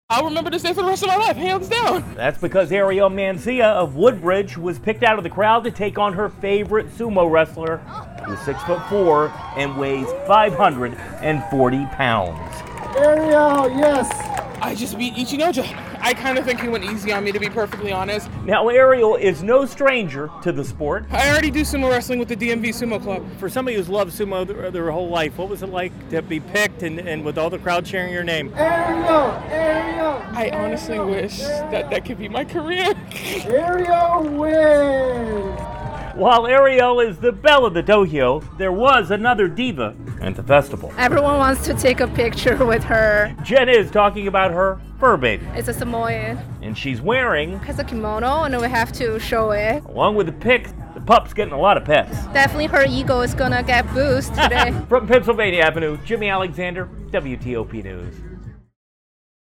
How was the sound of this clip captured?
checks out the Japanese Street Festival on Pennsylvania Avenue.